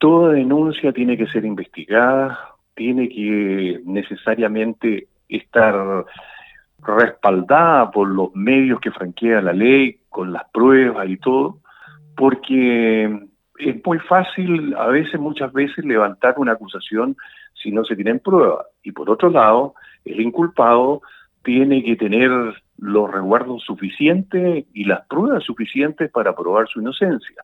Por su parte, el concejal UDI, Mario Jorquera, dijo que todas las denuncias se deben investigar y que la presunta víctima cuente con las pruebas necesarias, como también el acusado.